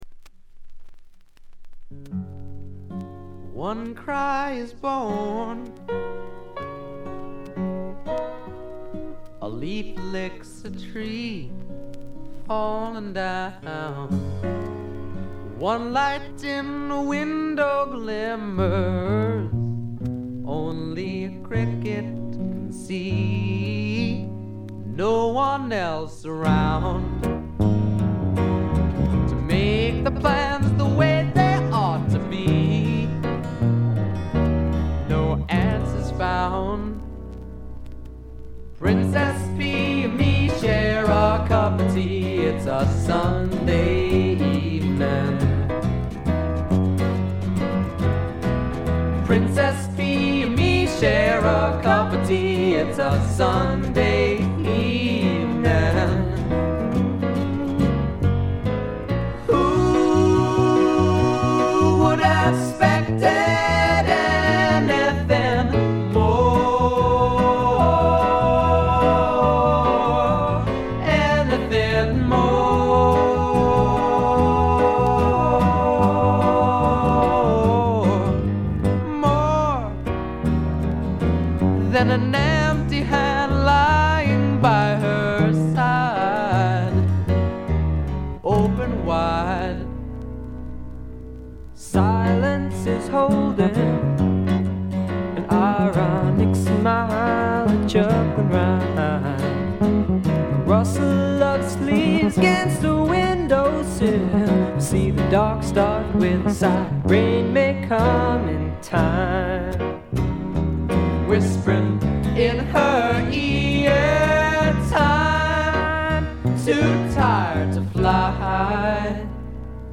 シンガーソングライター・デュオ
きらきらときらめくドリーミーフォーク的な感覚も素晴らしい。
試聴曲は現品からの取り込み音源です。
Percussion, Piano
Guitar, Percussion